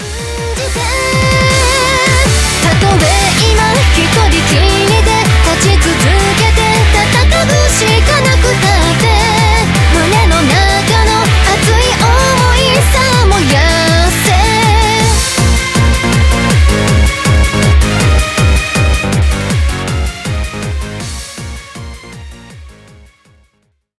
EUROTRANCE